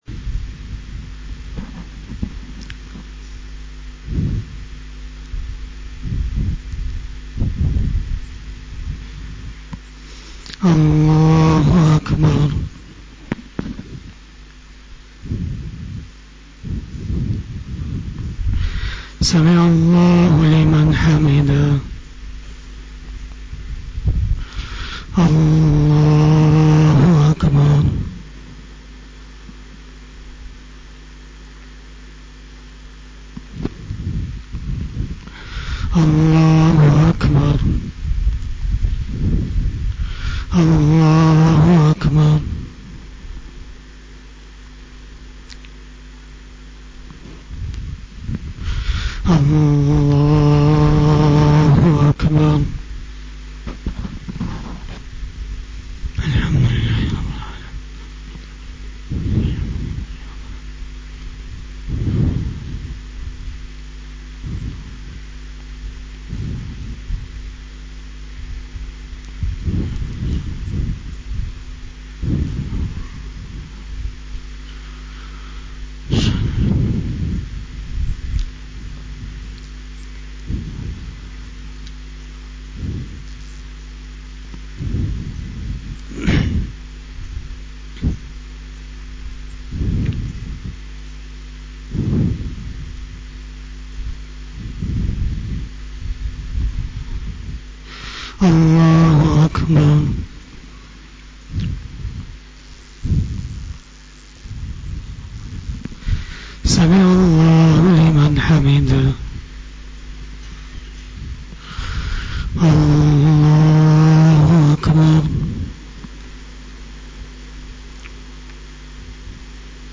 After Asar Namaz Bayan
بیان بعد نماز عصر